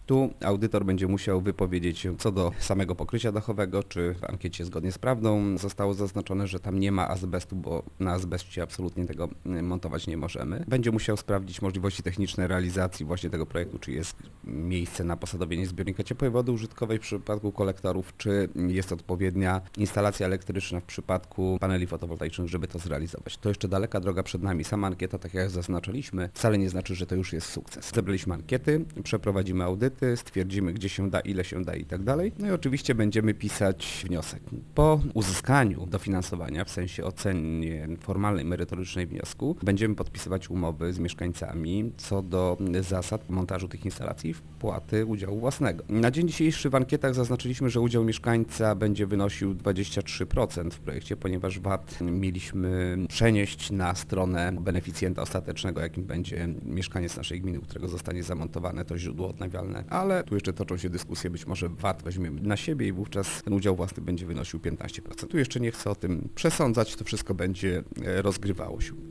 Samo złożenie ankiety, nawet w przypadku otrzymania przez samorząd dofinansowania, nie oznacza jednak automatycznie, że taka instalacja będzie u wszystkich zamontowana - zastrzega w rozmowie z Informacyjną Agencją Samorządową wójt Mariusz Osiak: